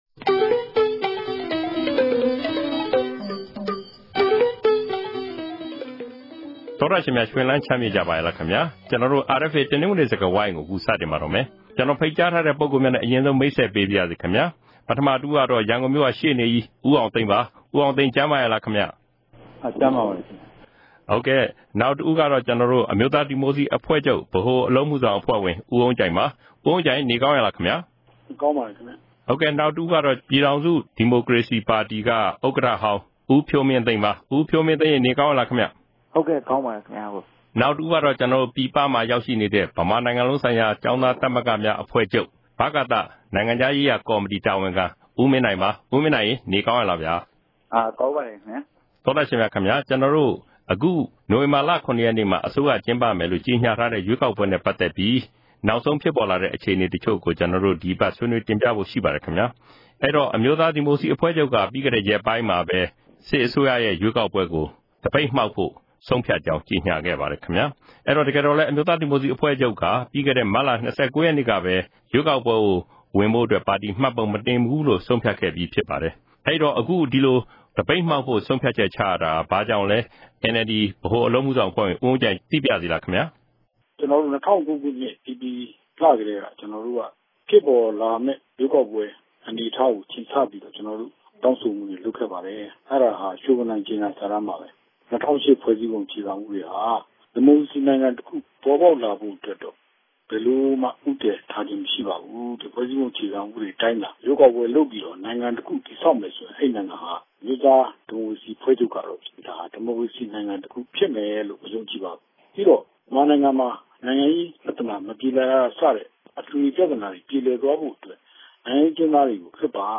တနင်္ဂနွေစကားဝိုင်း အစီအစဉ်မှာ NLD ပါတီက ရွေးကောက်ပွဲကို သပိတ်မှောက်လိုက်တာနဲ့ စပ်လျဉ်းပြီး ဆက်လက်ပေါ်ပေါက်လာမယ့် မြန်မာ့နိုင်ငံရေး အခြေအနေတွေကို ဆွေးနွေးထားကြပါတယ်။